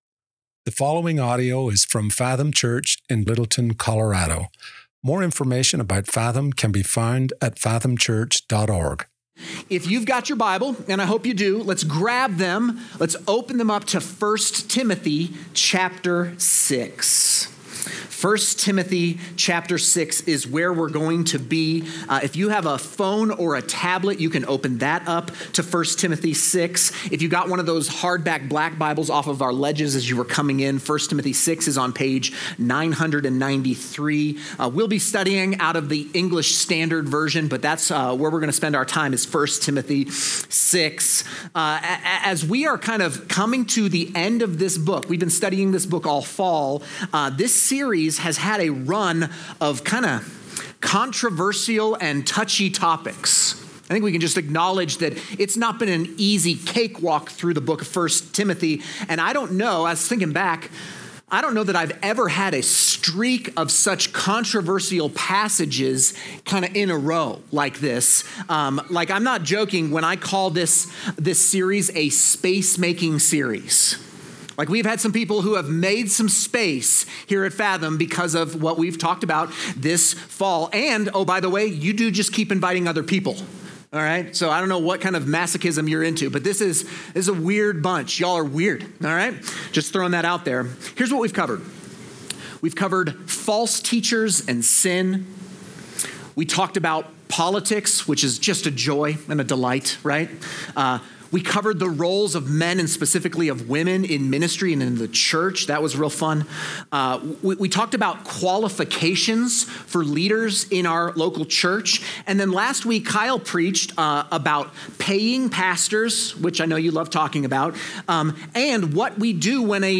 Fathom Church Sermons